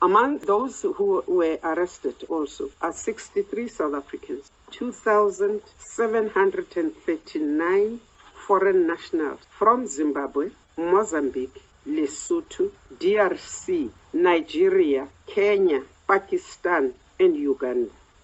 The Justice, Crime Prevention and Security Cluster held a media briefing today, to provide progress made in combatting organised crime. Modise says government will no longer tolerate foreign nationals who continue to attack South Africa’s economy through illegal mining activities: